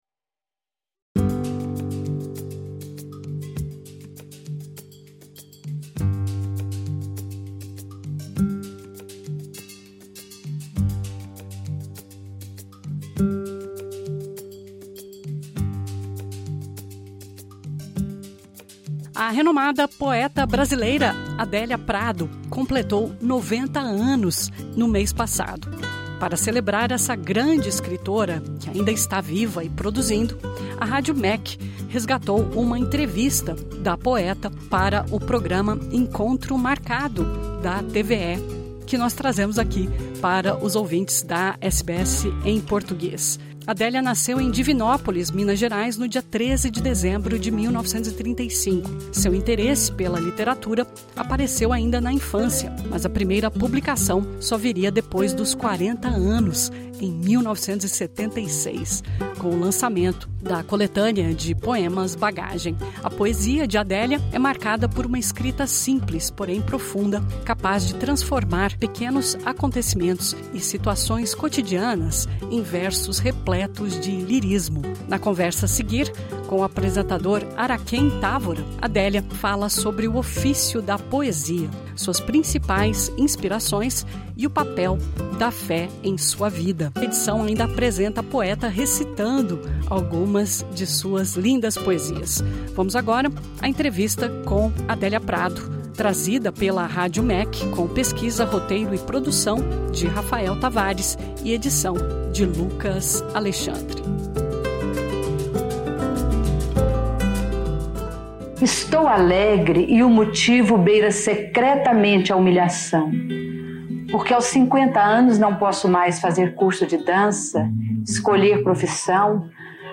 Entrevista trazida pela Rádio MEC.